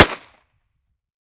38 SPL Revolver - Gunshot A 001.wav